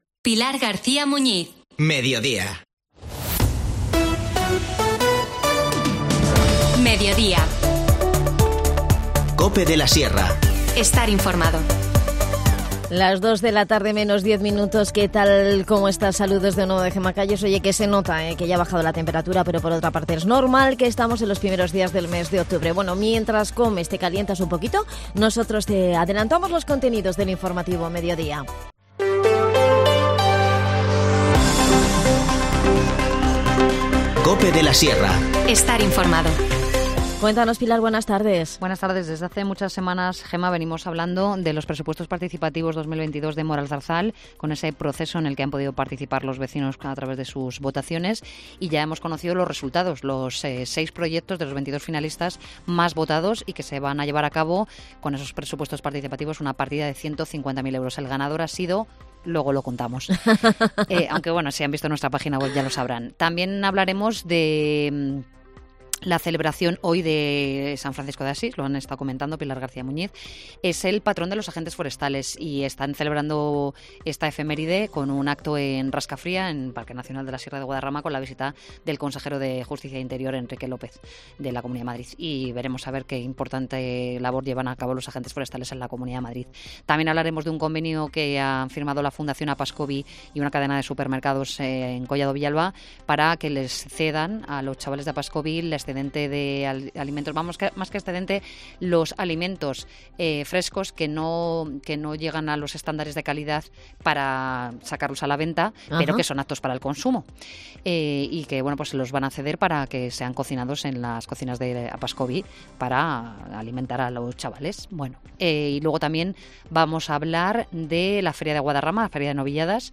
Hablamos con nuestra psicóloga